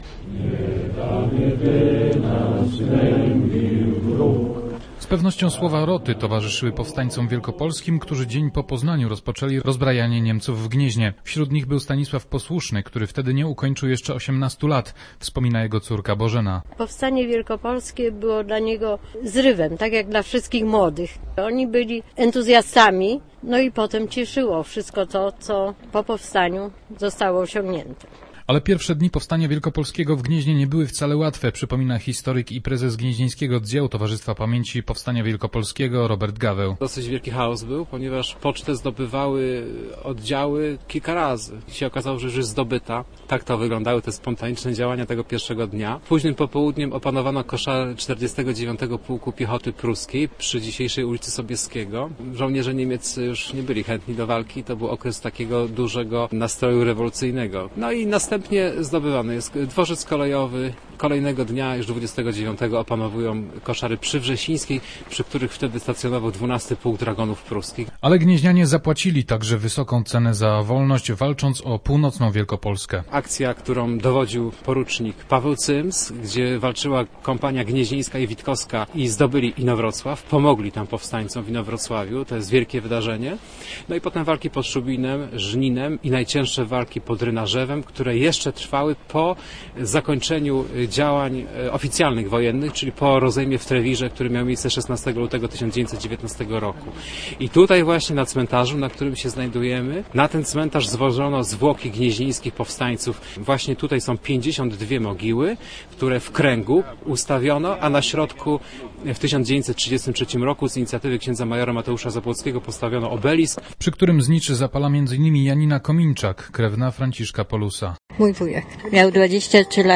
Przy mogiłach 56 bojowników, którzy zginęli w pierwszych dniach 1918 roku, mieszkańcy Gniezna uczcili 92 rocznicę Powstania Wielkopolskiego. Na cmentarzu św. Piotra złożono wiązanki kwiatów i odśpiewano Rotę.